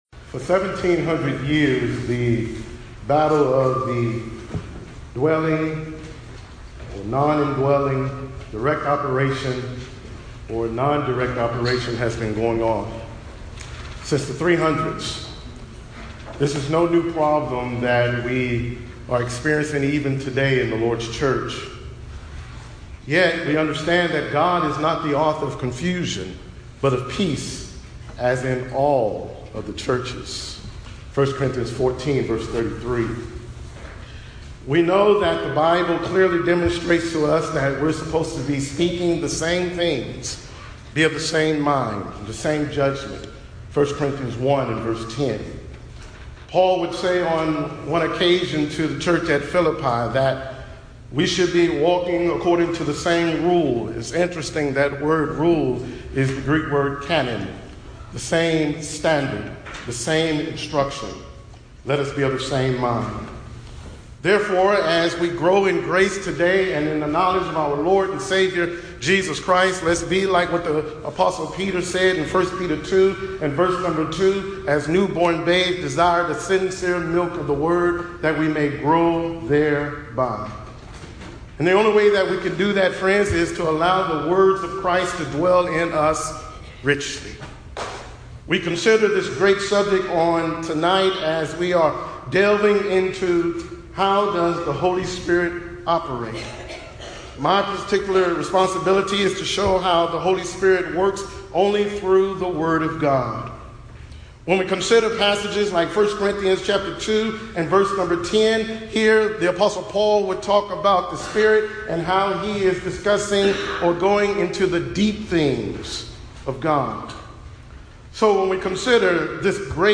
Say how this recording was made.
Event: 10th Annual Back to the Bible Lectures